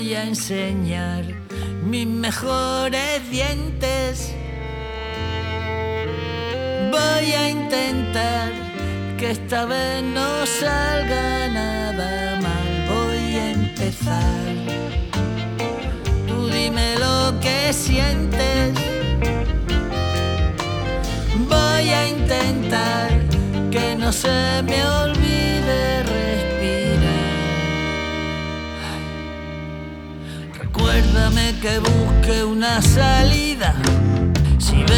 Adult Alternative Rock
Жанр: Рок / Альтернатива